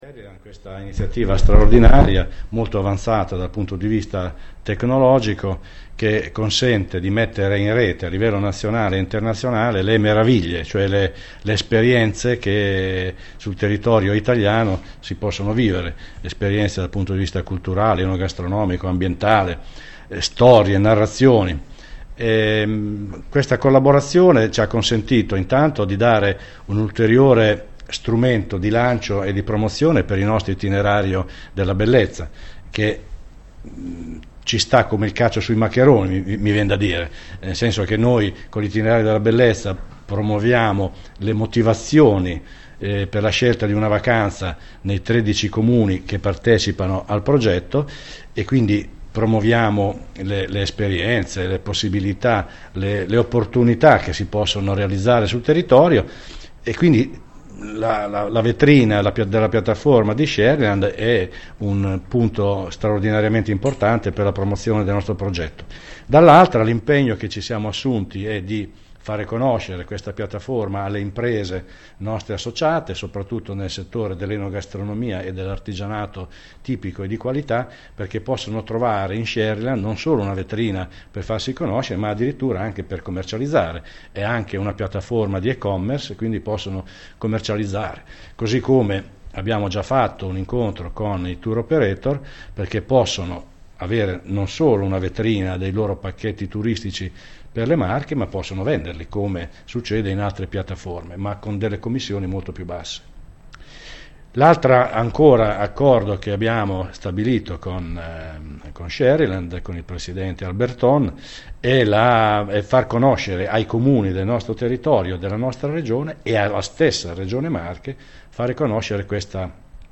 Confcommercio Marche Nord e Distretto Biologico Terre Marchigiane, siglano con SherryLand una partnership ricca di prospettive per il territorio e le comunità locali. Ai nostri microfoni